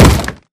Sound / Minecraft / mob / zombie / wood2.ogg
wood2.ogg